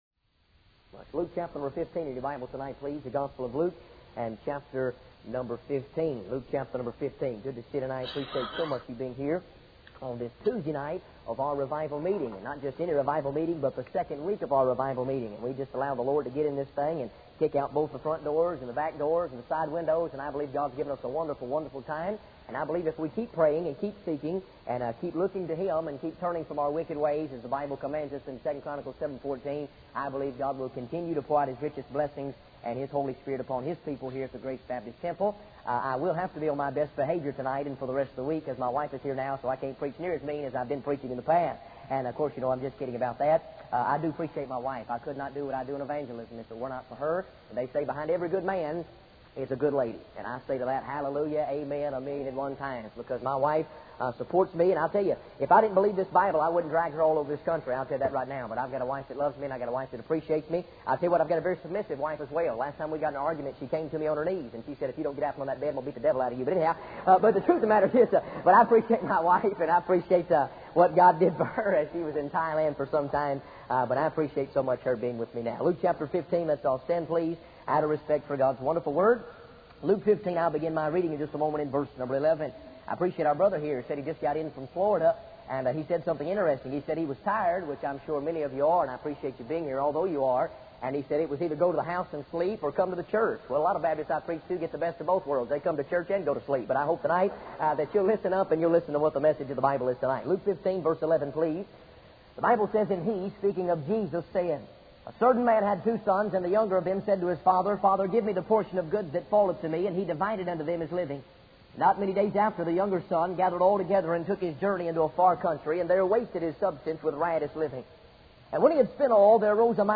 In this sermon, the preacher focuses on the story of the prodigal son from the Bible. He begins by discussing the distance that the son traveled when he left his father's house.